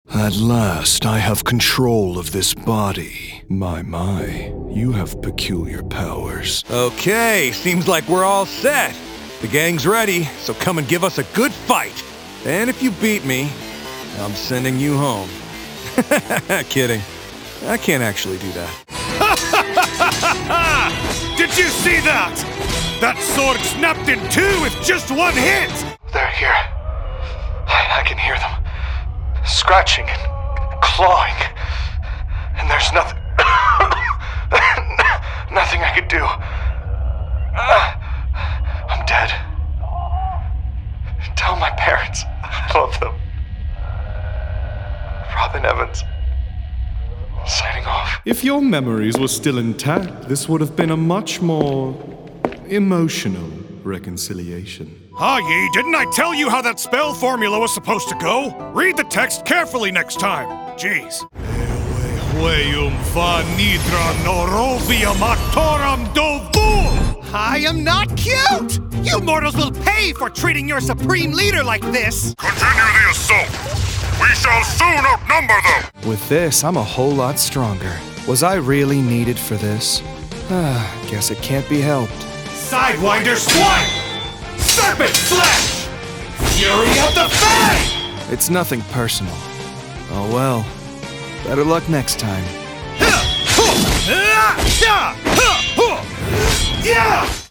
Teenager, Young Adult, Adult, Mature Adult
Has Own Studio
GAMING 🎮